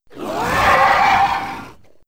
c_glizzom_atk1.wav